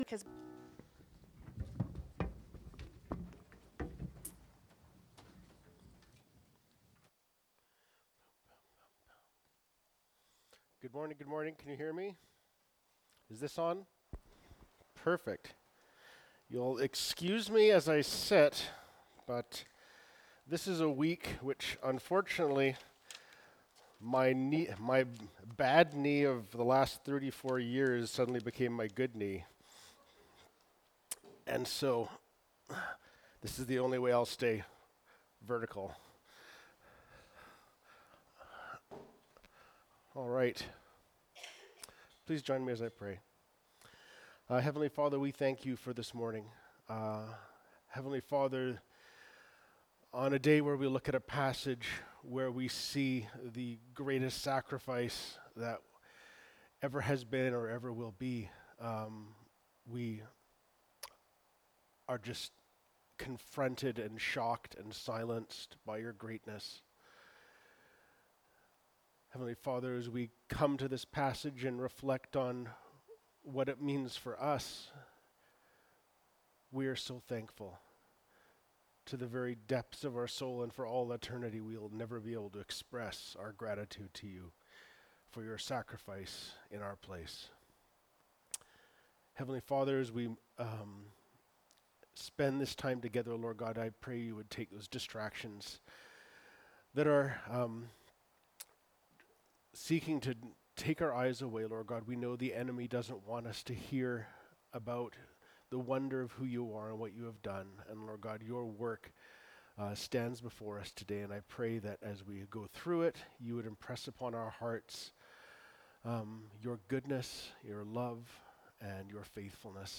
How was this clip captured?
Jun 16, 2024 Talking at the Table (John 19:16b-42) MP3 SUBSCRIBE on iTunes(Podcast) Notes Discussion Sermons in this Series This sermon was recorded at Grace Church Salmon Arm and preached in both campuses.